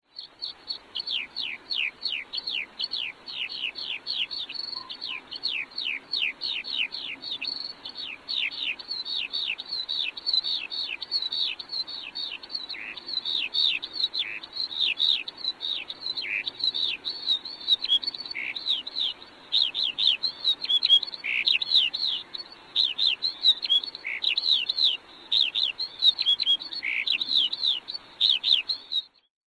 Der Gesang der Lerche klingt so (in das Bild klicken):
Lerchengesang aus 'Zauberhafte Vogelstimmen'
05lerche.mp3